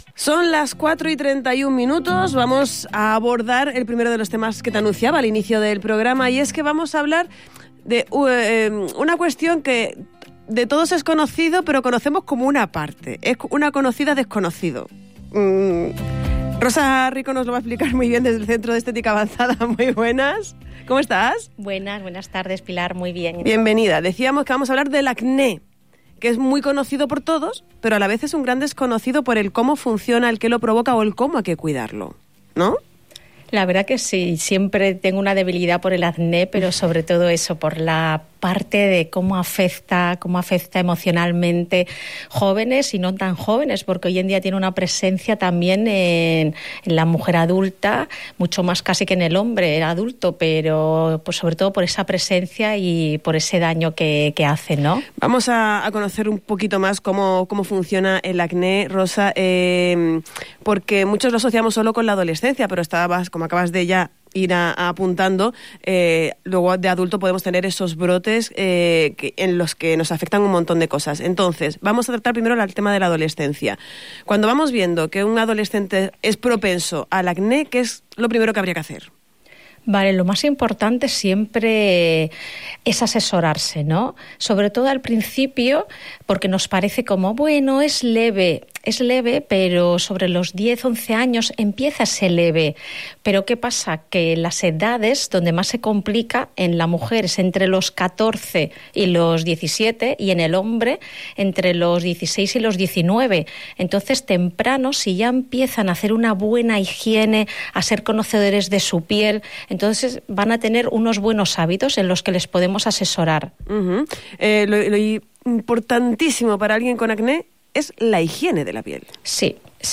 ha explicado en el programa El Tardeo de Radio Insular Fuerteventura